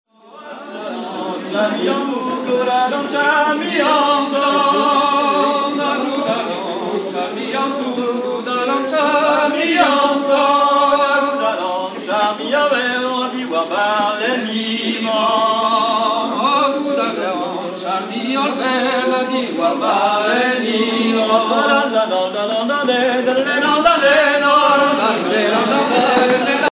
danse : gavotte bretonne
Genre laisse
10 ans de fêtes du chant à Bovel